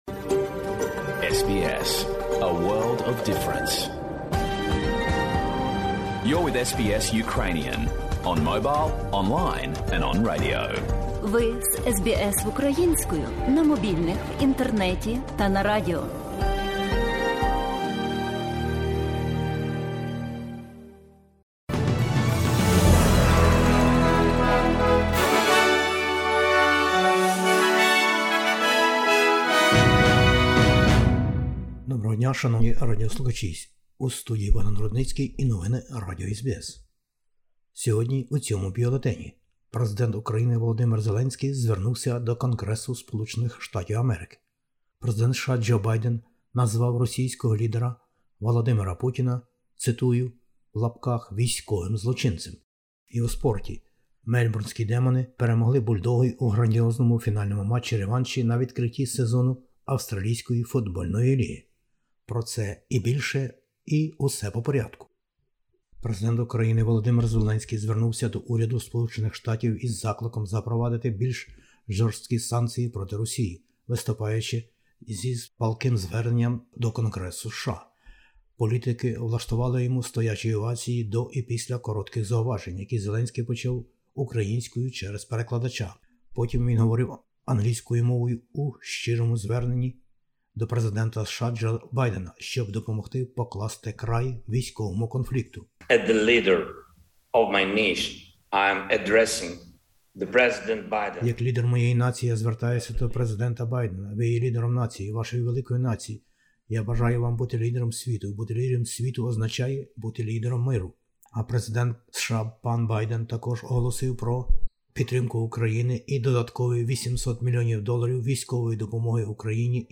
SBS новини українською - 17/03/2022